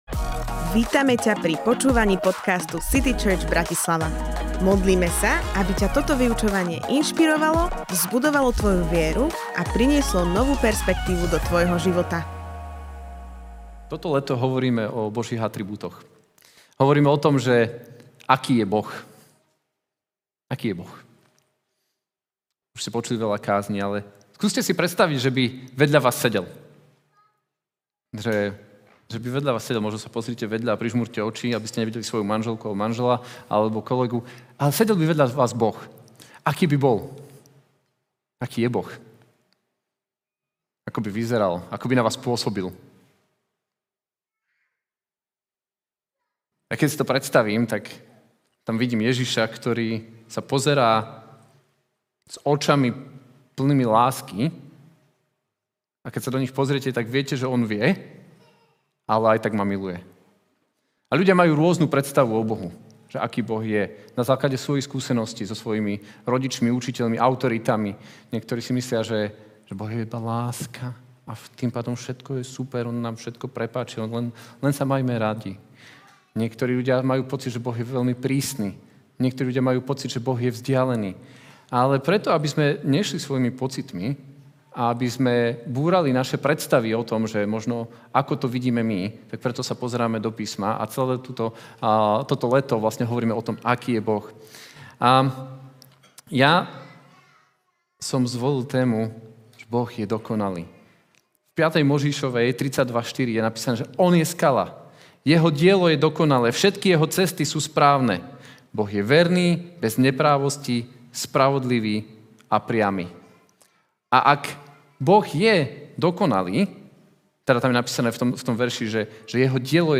Boh je dokonalý Kázeň týždňa Zo série kázní